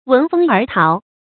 闻风而逃 wén fēng ér táo
闻风而逃发音
成语注音ㄨㄣˊ ㄈㄥ ㄦˊ ㄊㄠˊ